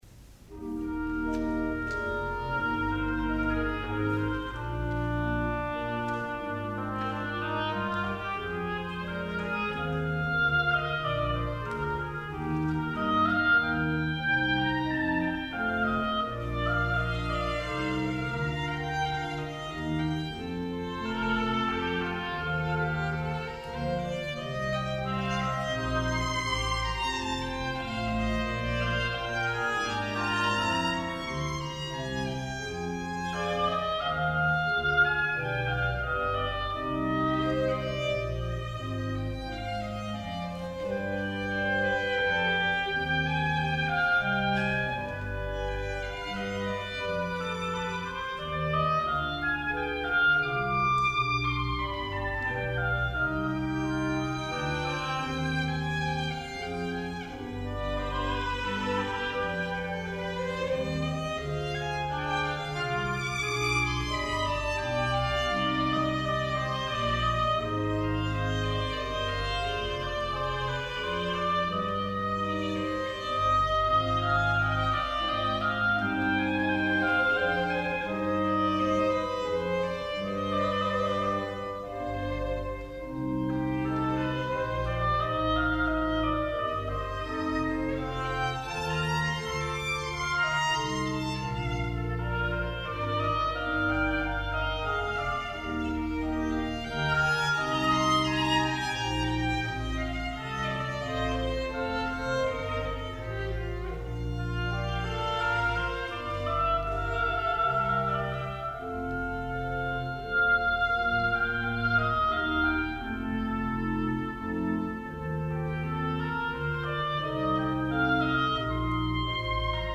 violin
oboe